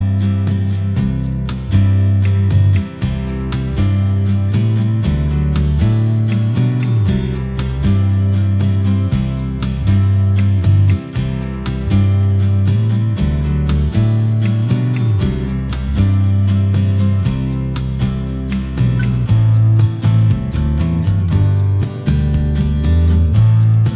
Minus Electric Guitar Indie / Alternative 5:15 Buy £1.50